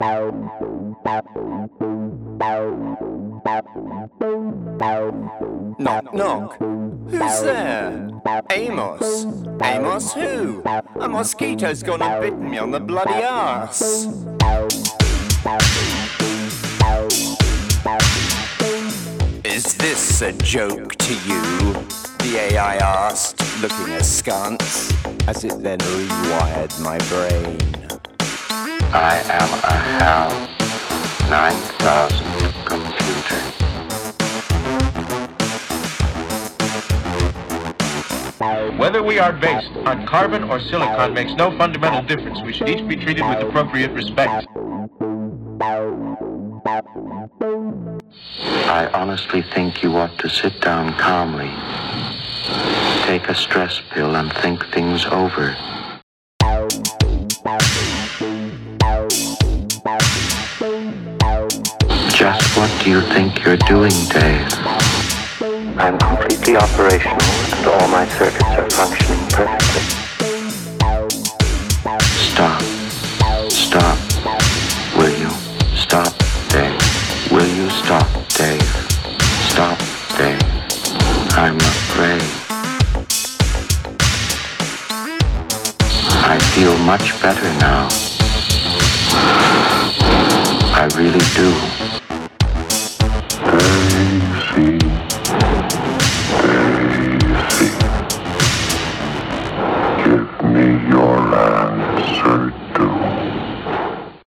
I enjoy the groove.